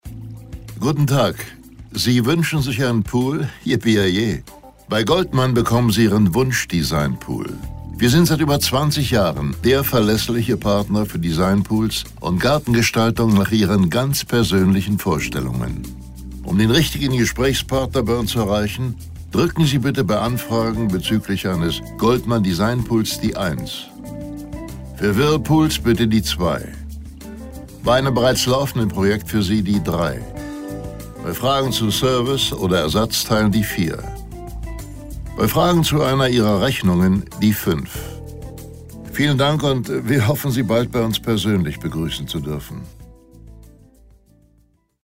Anrufbeantworter Ansage Tag – mit der deutschen Bruce Willis Stimme